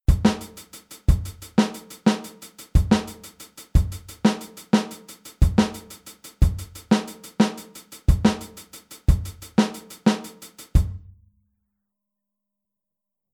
Aufteilung linke und rechte Hand auf HiHat und Snare
Zum ersten Mal greift nun die linke Hand auf der Snare ins Geschehen ein. Dadurch erhalten wir wieder einen echten 16tel-Groove (wie Nr. 2). Die linke Hand muss also sofort nach der Zählzeit 1 runter vom HiHat auf die Snare.
Groove04-16tel.mp3